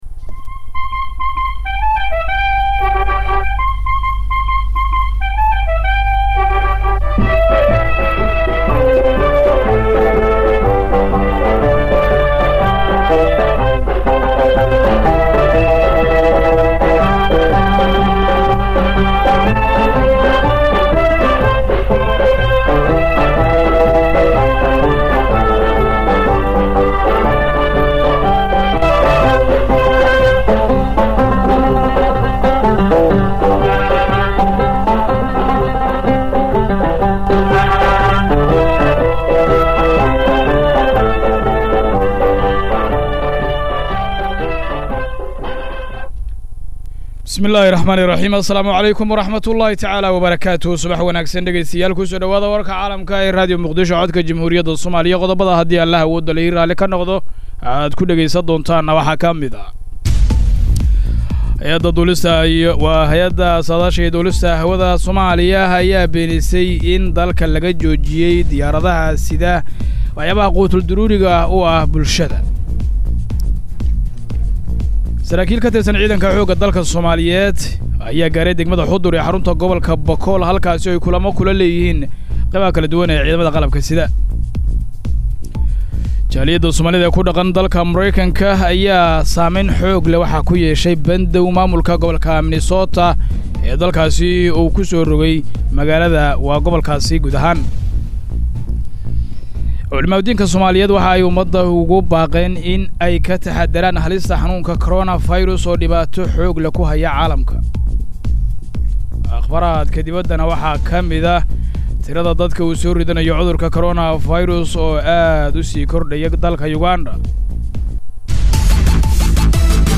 Dhageyso warka subax ee radiomuqdisho axad 29.3.2020